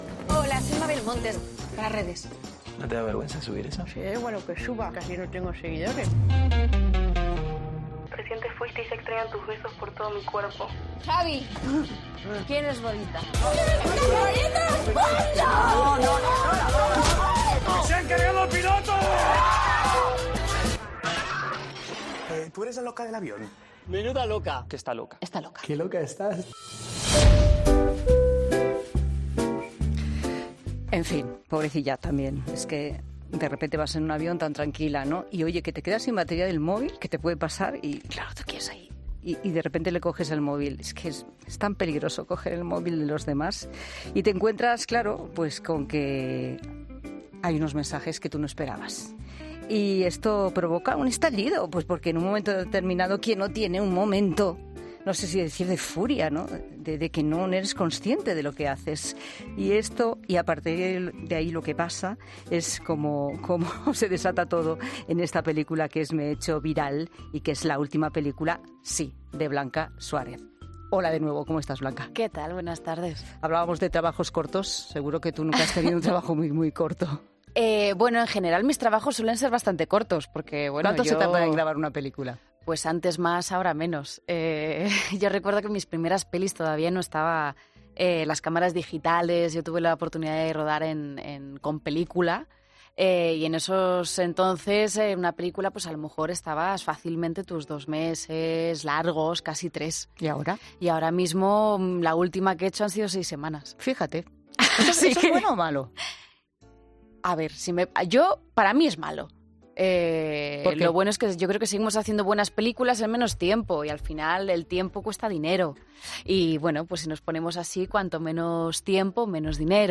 La actriz ha pasado por los estudios de COPE para presentar su nueva película 'Me he hecho viral', que se estrenó el pasado 11 de octubre en cines
La actriz Blanca Suárez ha pasado este lunes por los micrófonos de 'La Tarde' para hablar de su última película 'Me he hecho viral'.